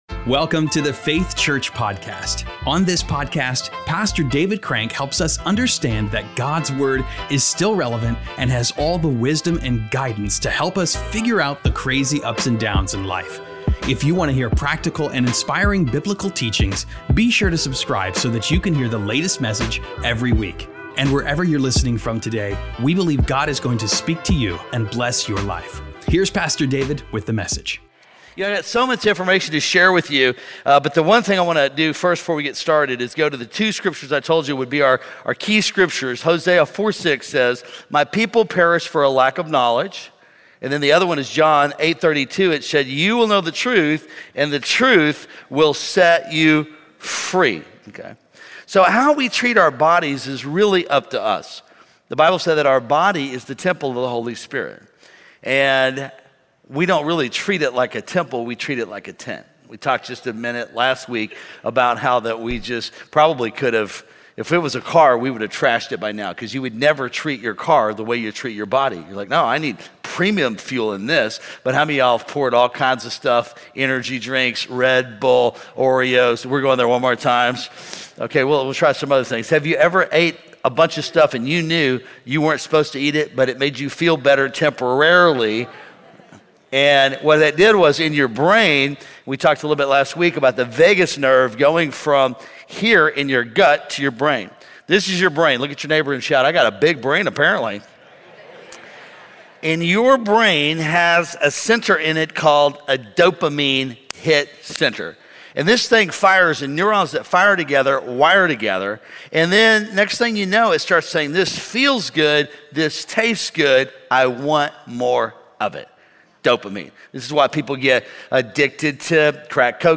In Part 2 of his teaching on “The Mind-Gut Connection